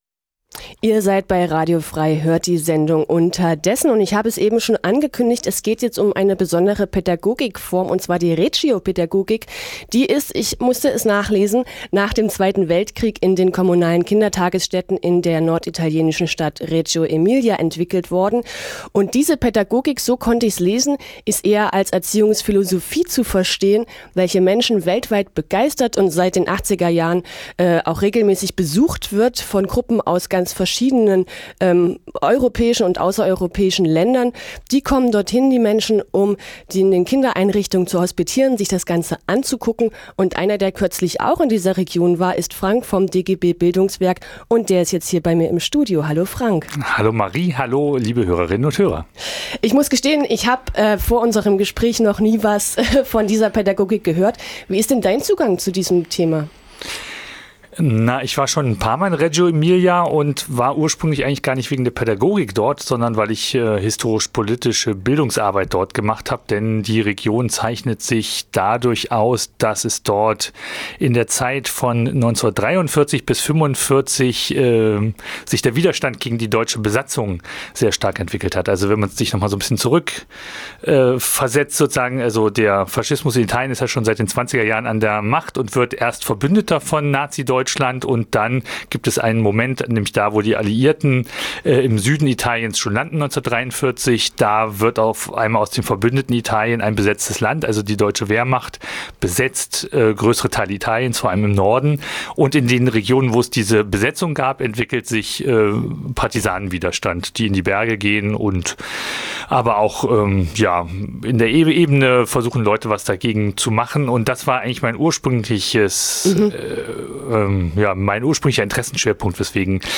[Neu: Interview]